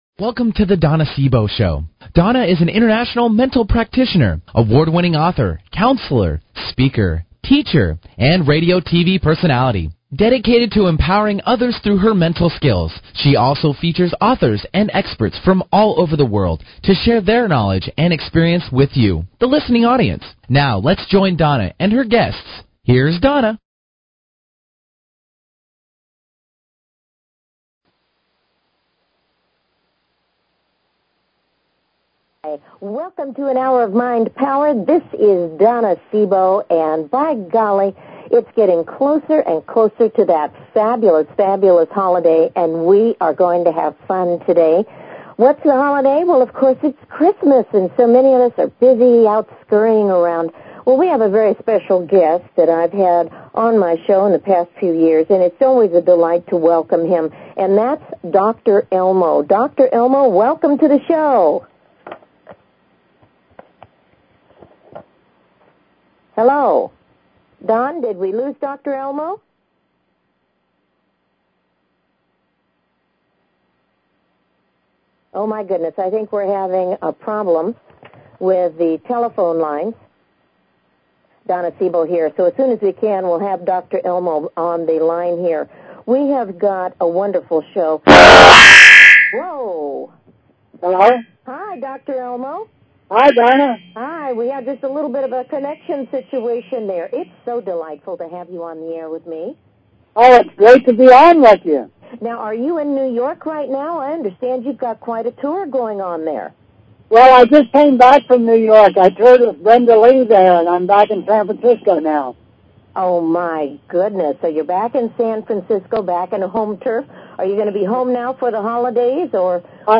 Talk Show Episode
Join us for a fun filled musical treat.